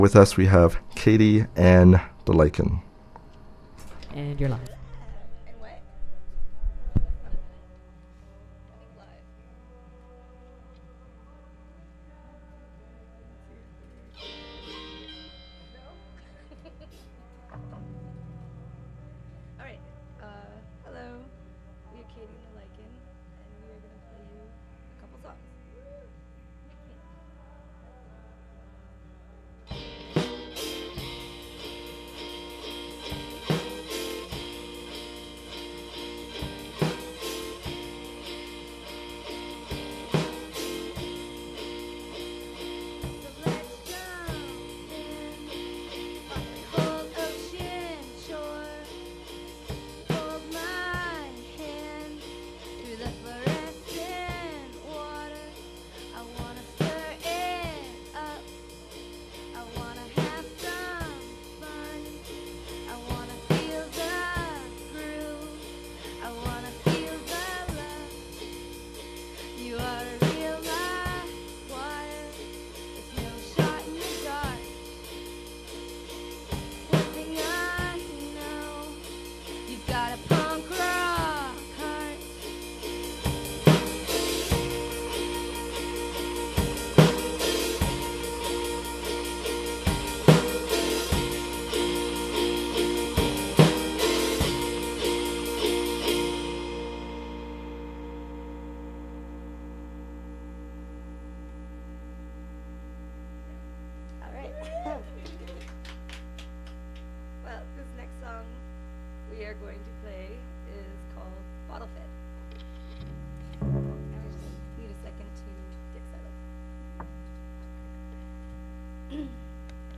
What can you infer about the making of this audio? Click here to listen to the Podcast of our live show!